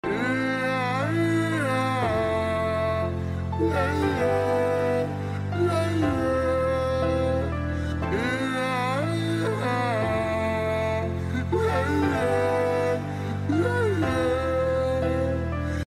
The eiyaa eiyaa aa cinematic version sound button is from our meme soundboard library